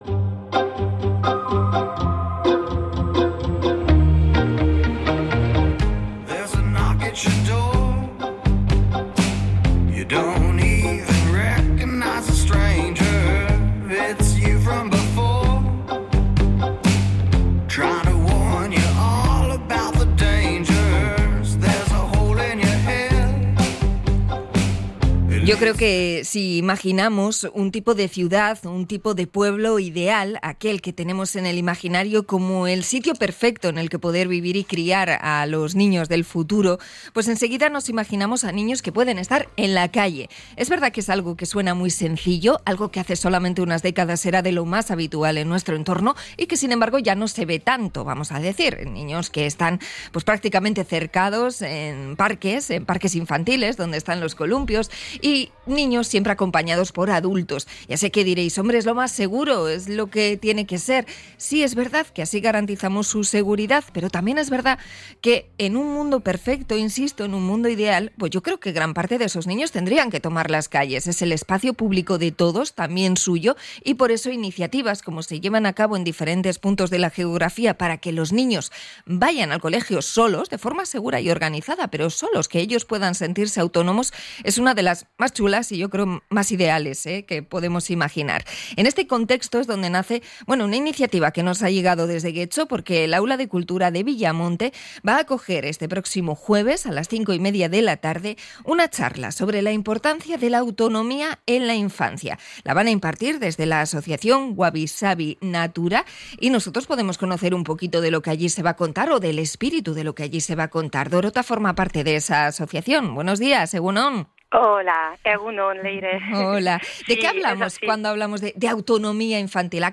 Entrevista a Wabi Sabi Natura sobre la autonomía infantil